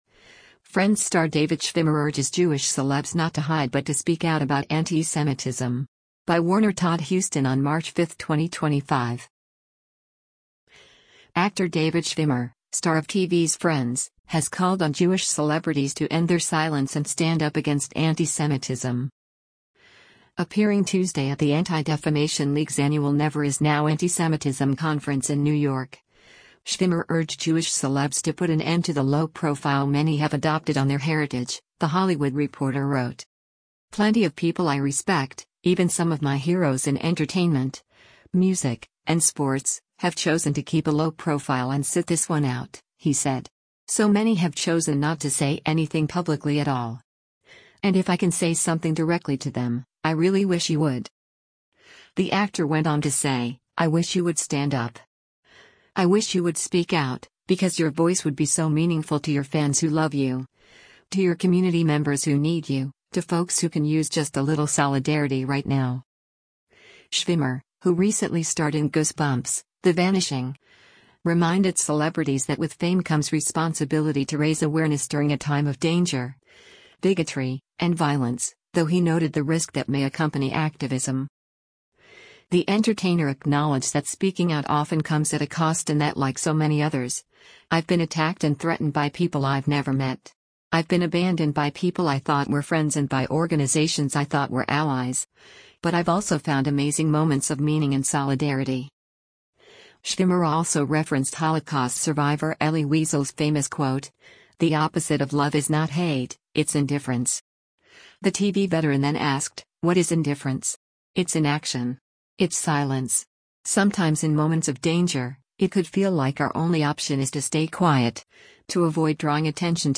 Appearing Tuesday at the Anti-Defamation League’s annual Never Is Now antisemitism conference in New York, Schwimmer urged Jewish celebs to put an end to the “low profile” many have adopted on their heritage, the Hollywood Reporter wrote.